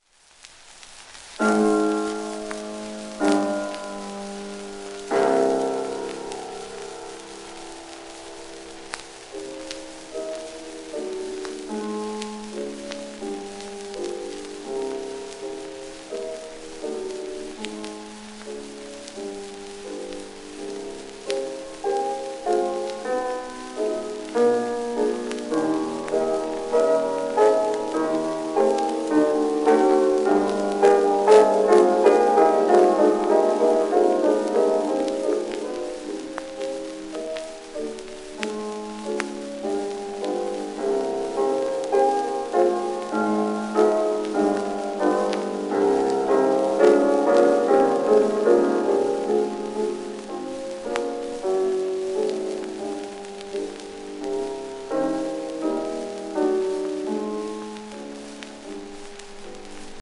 ウィーンで学びブゾーニとも共演を果たしたイギリスの女性ピアニスト。
旧 旧吹込みの略、電気録音以前の機械式録音盤（ラッパ吹込み）